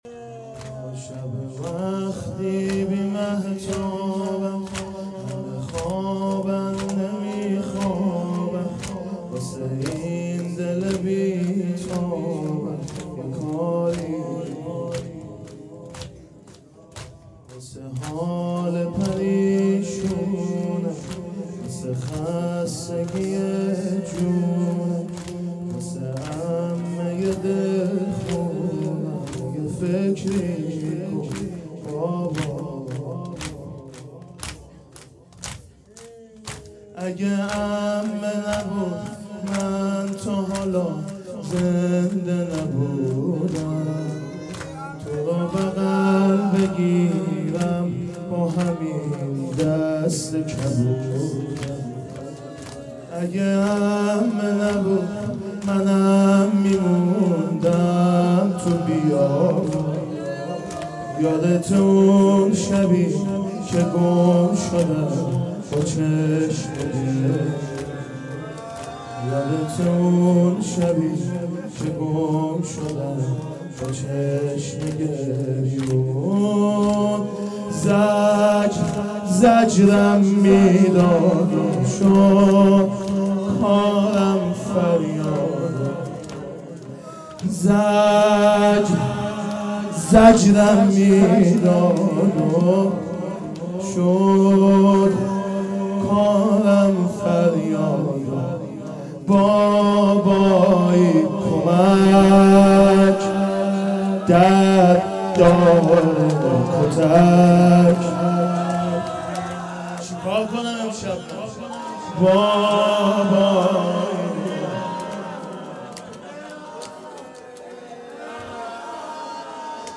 محرم 1440 _ شب سوم